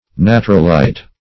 Natrolite \Na"tro*lite\ (?; 277), n. [Natron + -lite: cf. F.